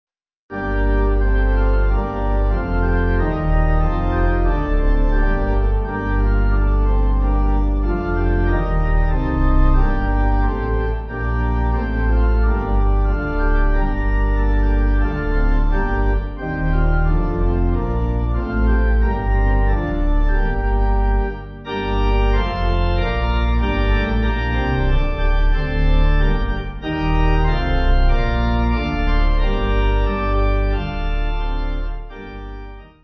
Organ
(CM)   5/Gm